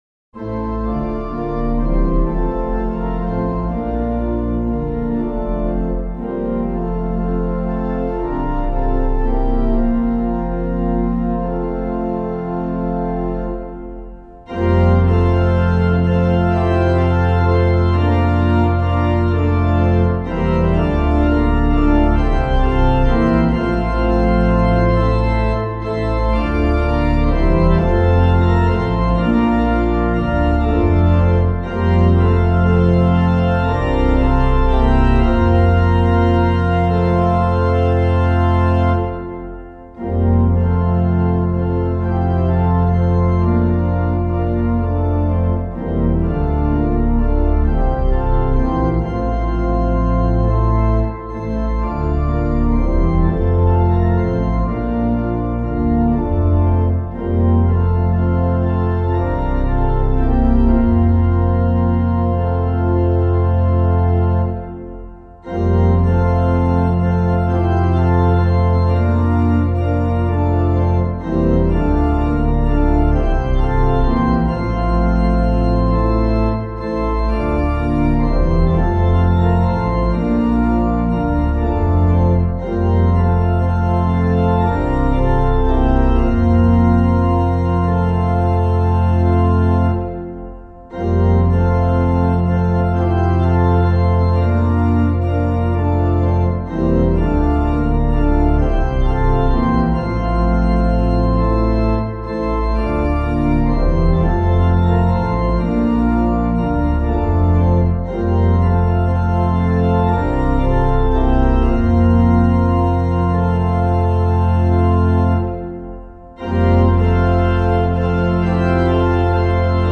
Composer:    traditional American melody.